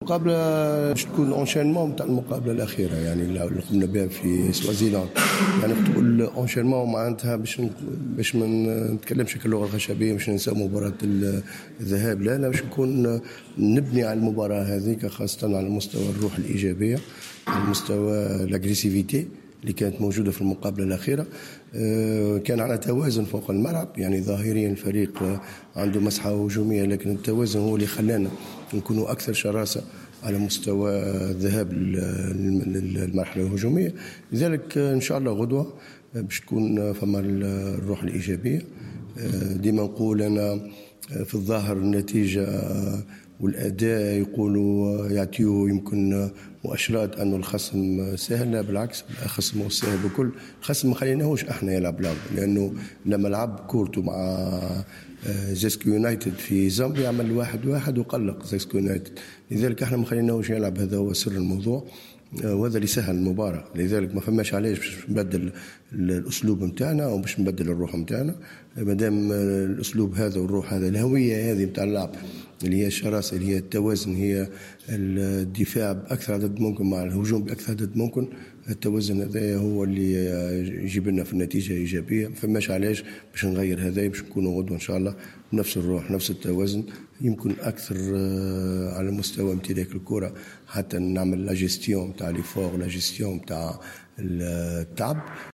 عقد مدرب النجم الرياضي الساحلي شهاب الليلي ندوة صحفية بقاعة المؤتمرات الصحفية بالملعب الأولمبي بسوسة بحضور اللاعب أمين الشرميطي للحديث حول مباراة الجولة الرابعة من منافسات المجموعة الرابعة لكأس رابطة أبطال إفريقيا أمام مبابان سوالوز السوازلاندي.